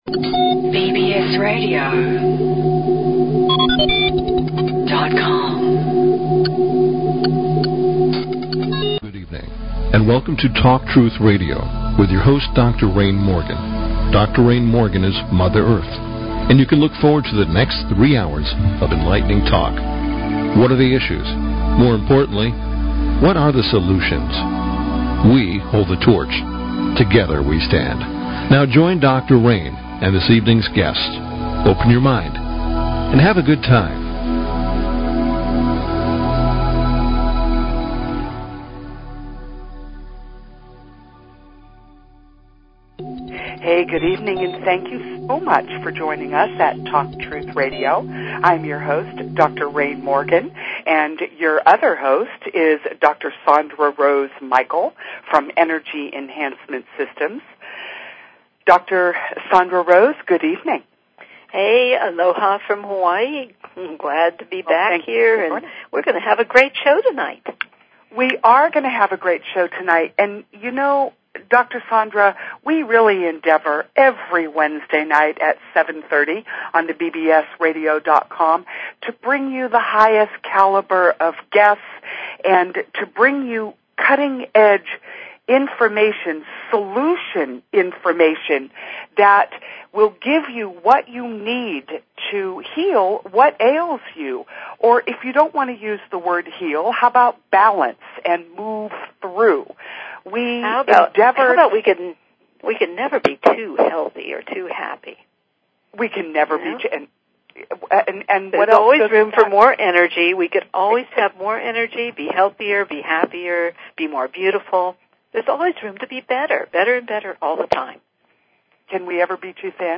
Talk Show Episode, Audio Podcast, Talk_Truth_Radio and Courtesy of BBS Radio on , show guests , about , categorized as